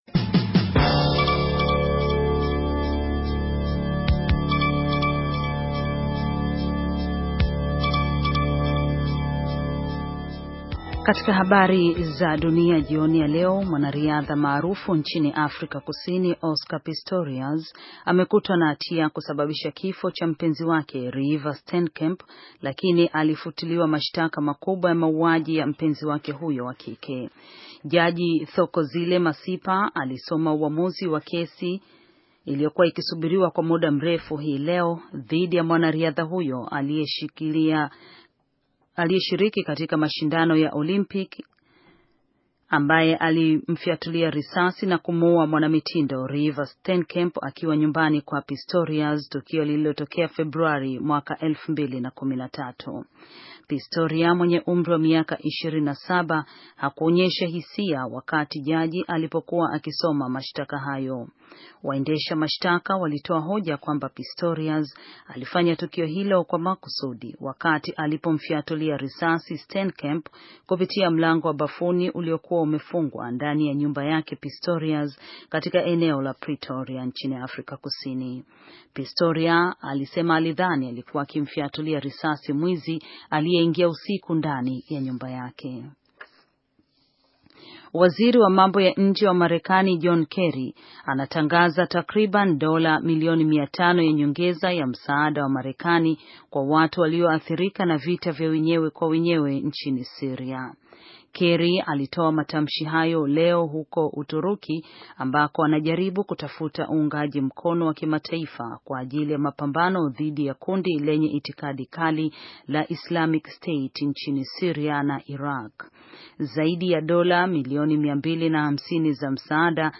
Taarifa ya habari - 6:41